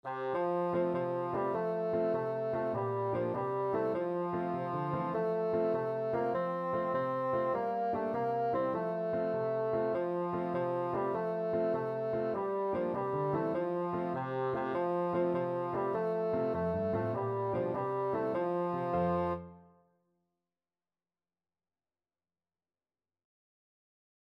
Bassoon
F major (Sounding Pitch) (View more F major Music for Bassoon )
6/8 (View more 6/8 Music)
C4-C5
Quick two in a bar . = c.100
Traditional (View more Traditional Bassoon Music)